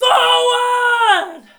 battle-cry-4.mp3